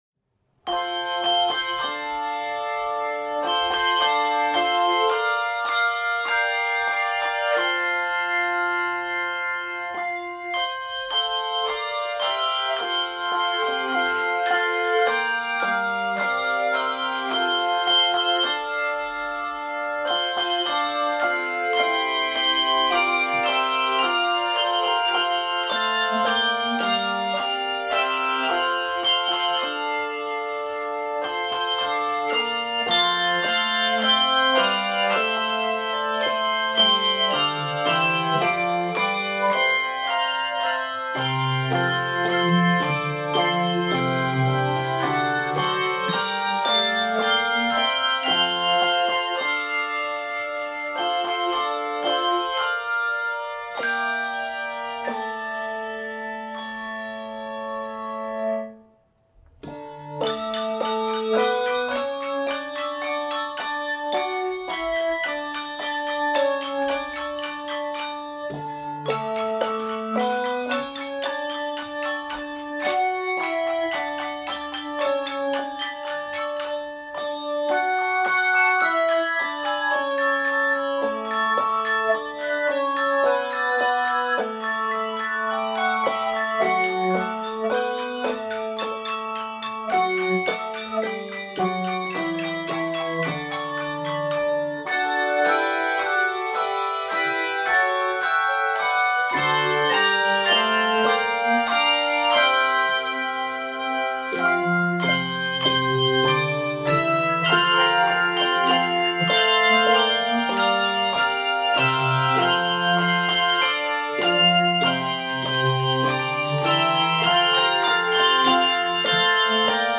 handbell ensemble